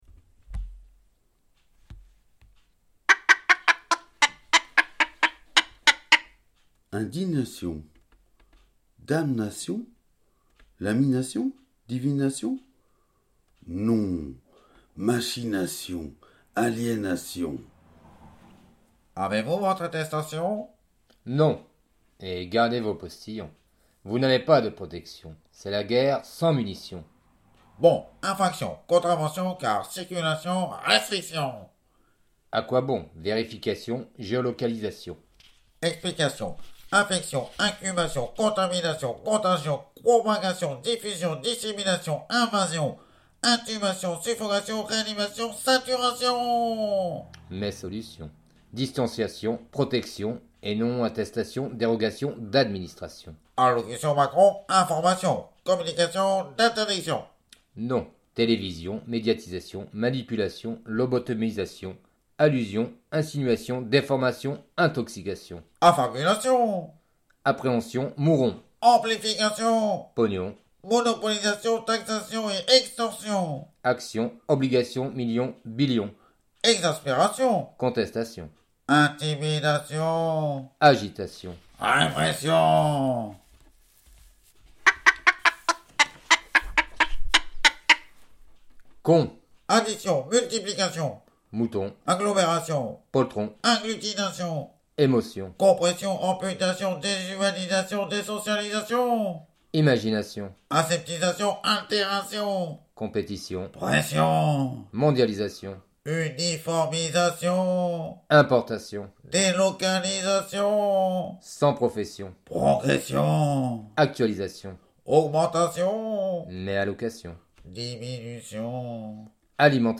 Indignation.mp3 (2.52 Mo) (Lecture à deux voix) ***** « Dame nation : l'amie nation, divine nation?